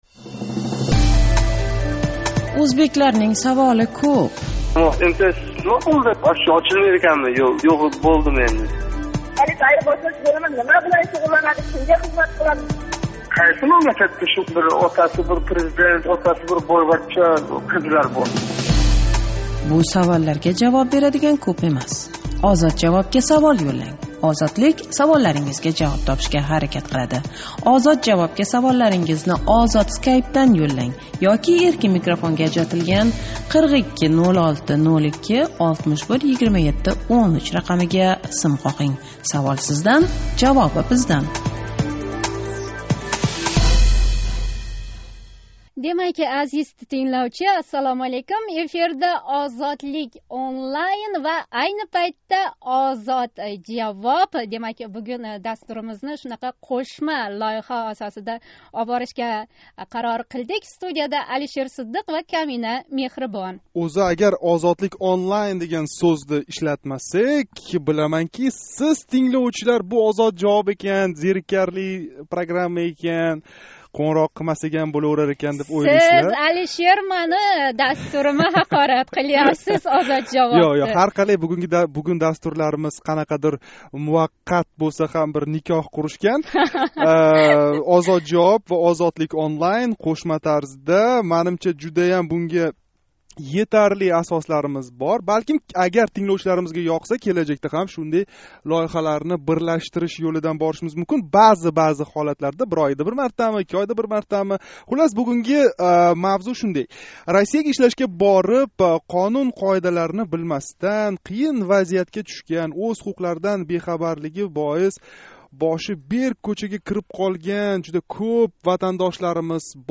OzodJavob: Ҳуқуқшунослар жонли эфирда меҳнат муҳожирлари саволларига жавоб берди!!!